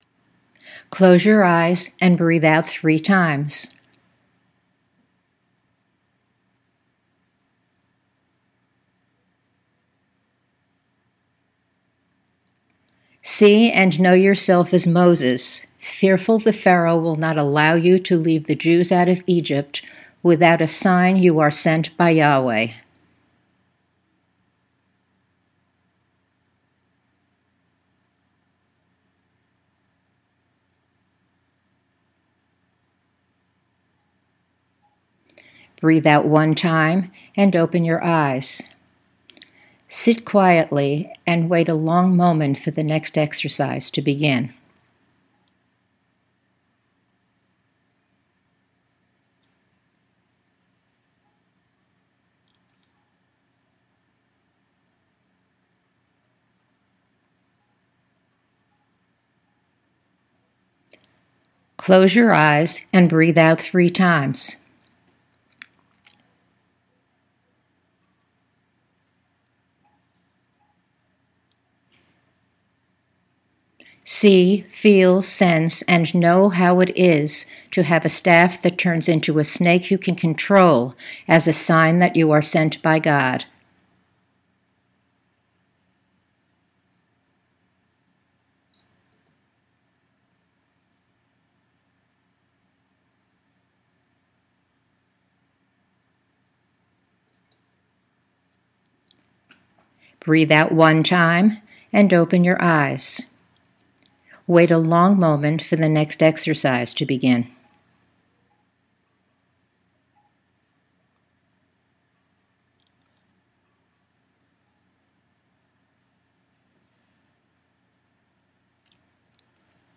Audio  Imagery for The Fourth Sunday of Lent, the Gospels of John, Chapter 3